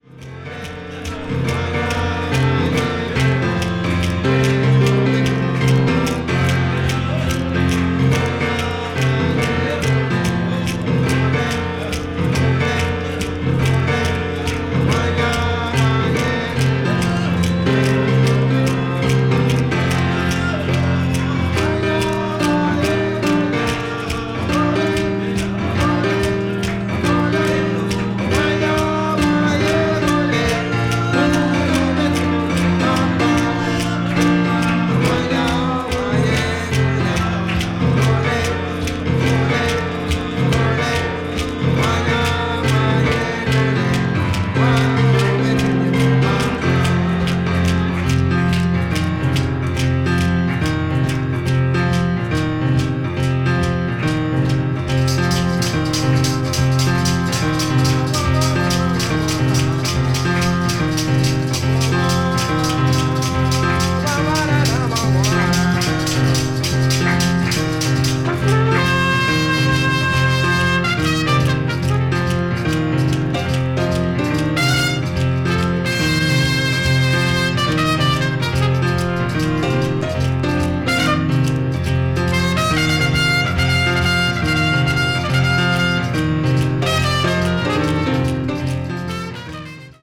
contemporary jazz   ethnic jazz   free jazz